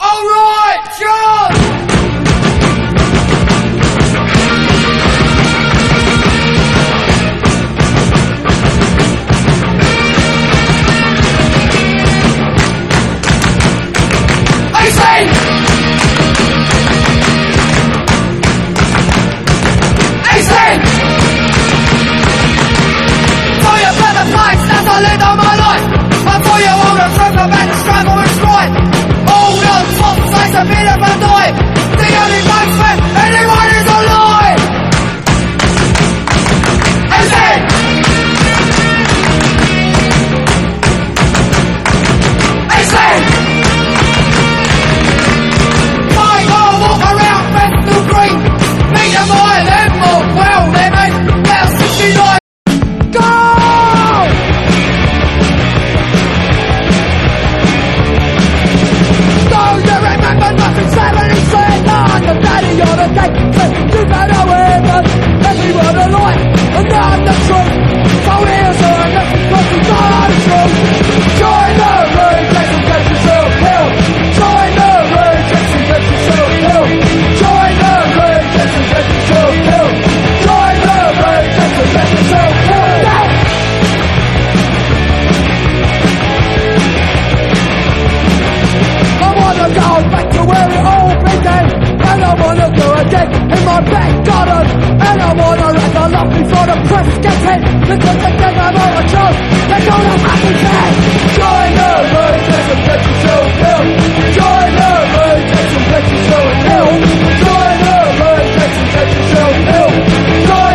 EASY LISTENING / OTHER / RHYTHM & BLUES / OLDIES / DRUM
強力ドラム・グルーヴ2曲をカップリングした最強7インチ！